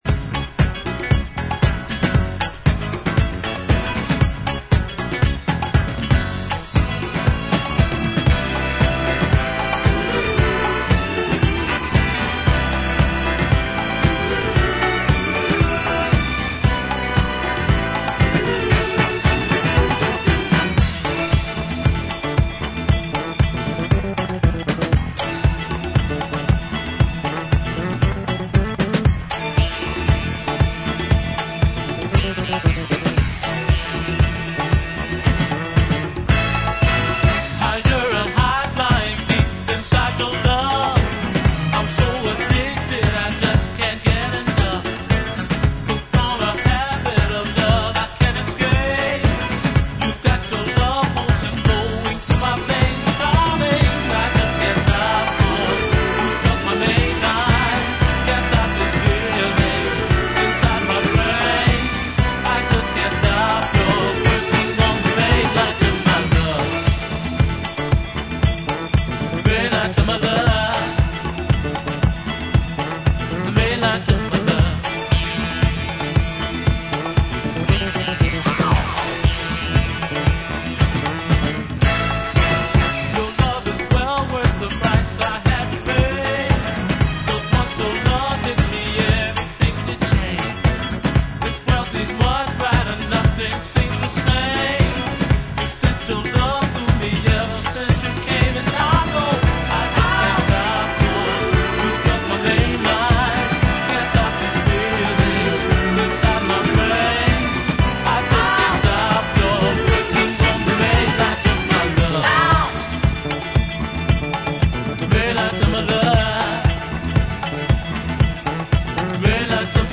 ジャンル(スタイル) DISCO / DANCE CLASSIC